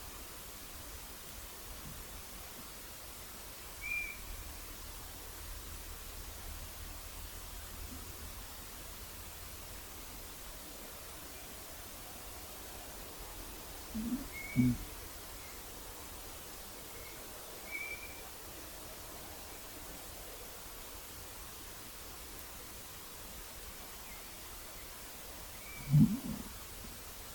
Birds -> Waders ->
European Golden Plover, Pluvialis apricaria
StatusVoice, calls heard
Notesnakts ieraksts